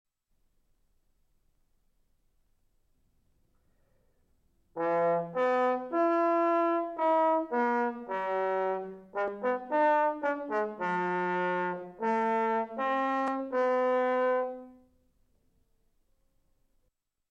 Audition Tape (April 1984)
Douglas Yeo, bass trombone.